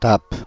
Ääntäminen
IPA : /tæp/